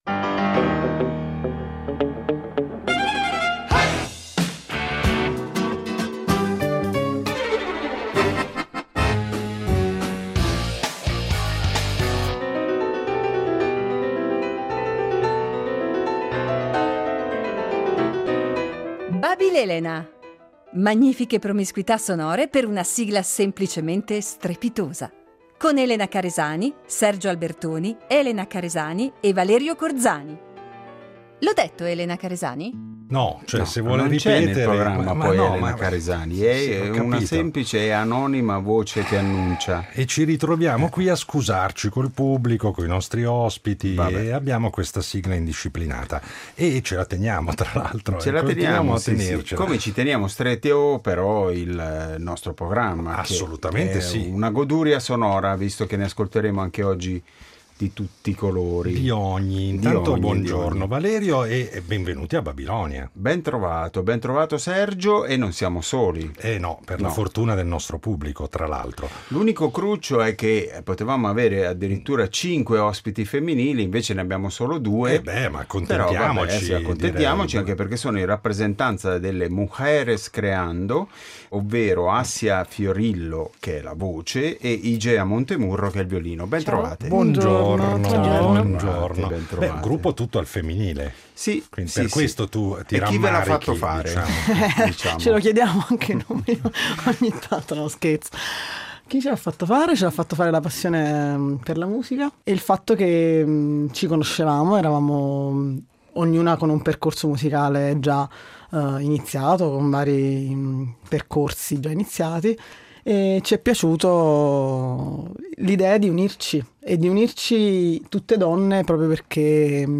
Oggi abbiamo la fortuna di ospitare la band napoletana Mujeres Creando al completo